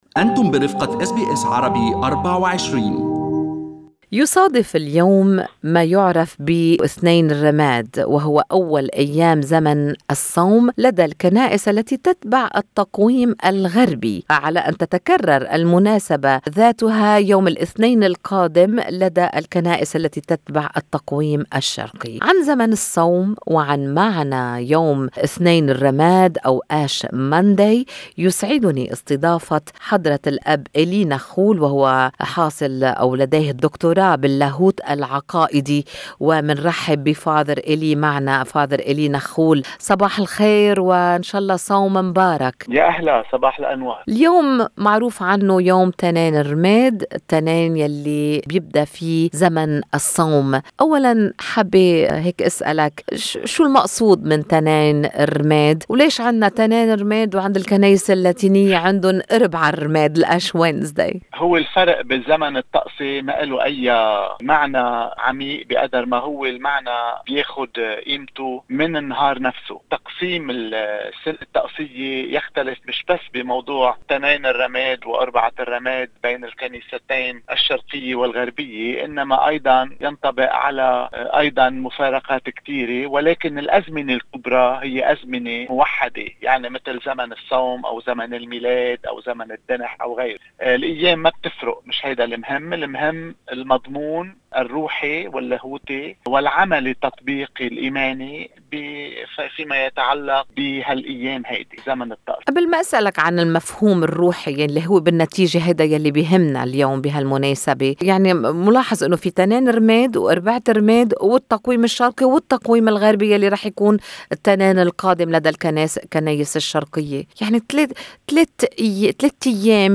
في حديث مع أس بي أس عربي 24